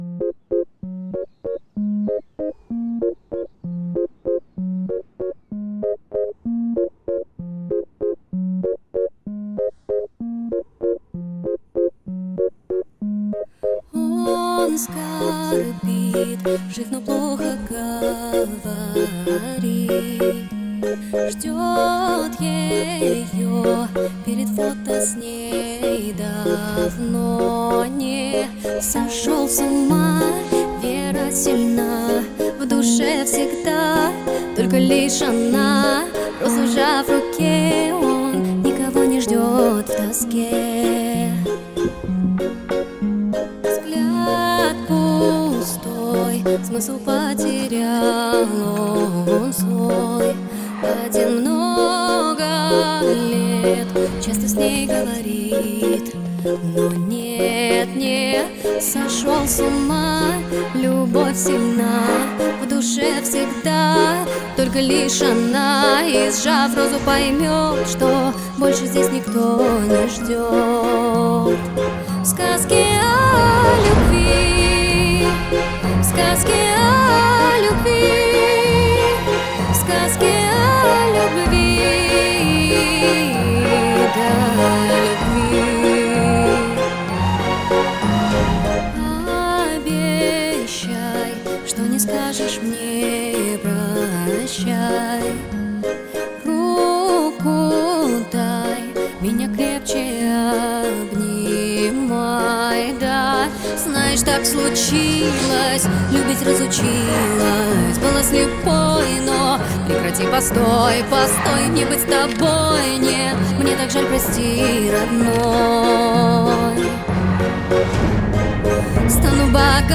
Текст песни